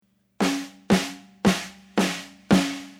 Here’s a second example featuring a snare drum.
First, the original snare drum track:
tipDistortion-SnareClean.mp3